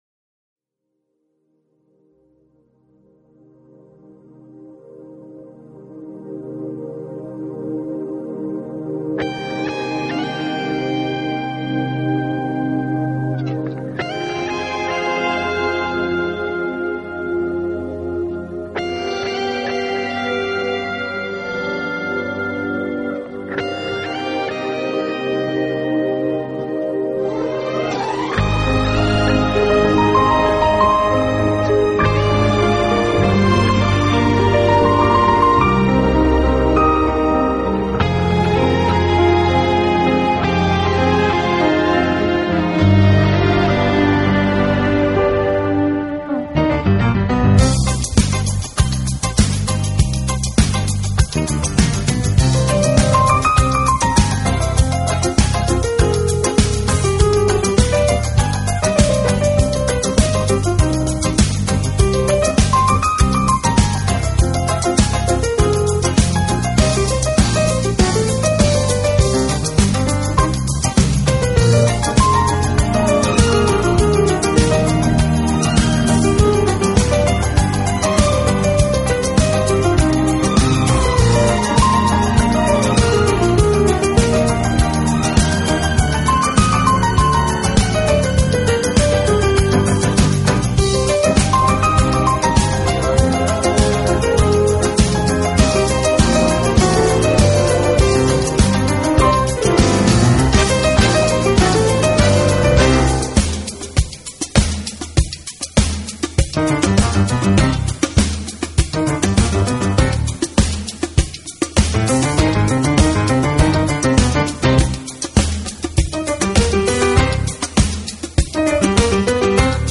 Genre: Latin Piano